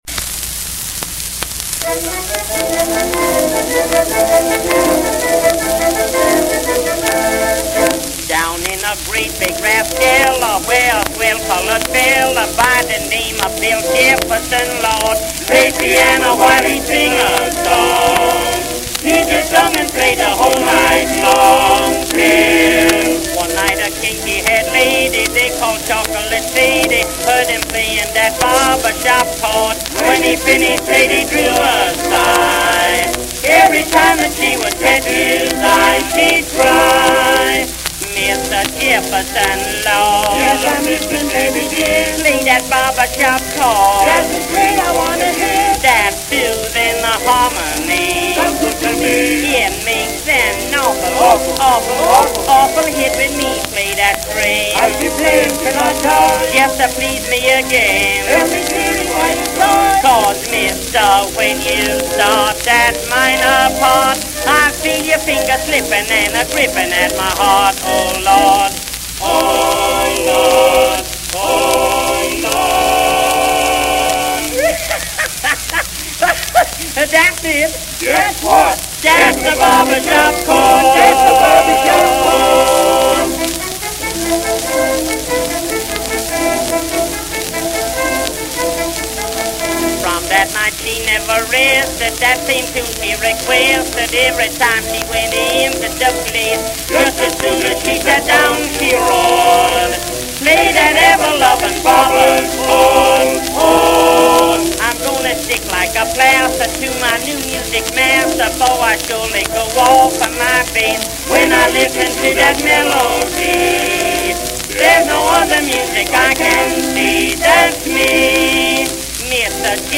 Old Quartet Records
The quality ranges from very clear to very scratchy.
The volume varies as well.